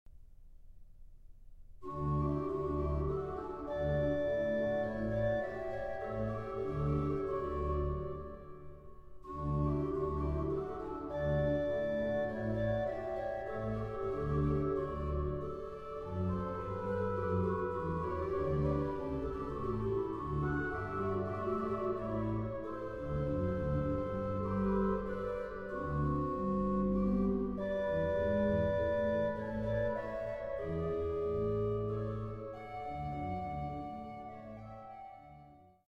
in Middelburg, The Netherlands